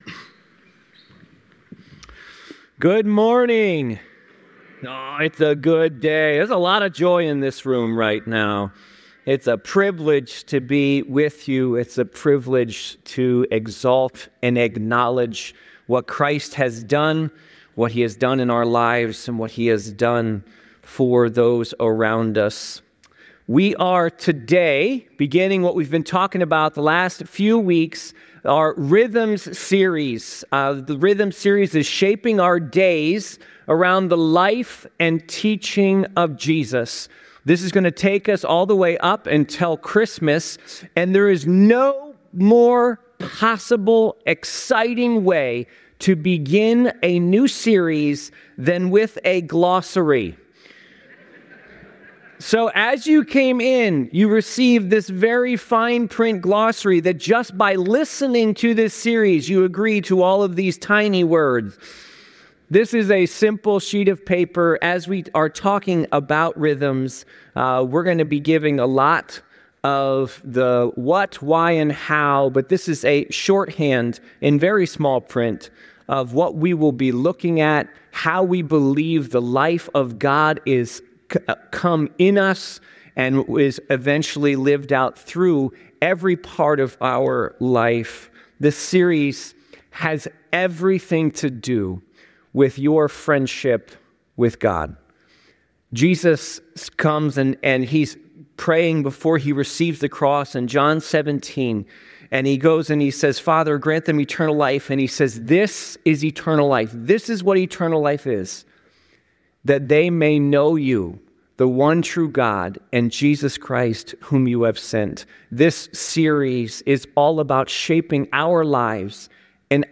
The sermon encourages participants to be conscious of and share their spiritual stories, emphasizing that God’s work in their lives is holy and miraculous, and that spiritual formation often occurs through community and shared experiences.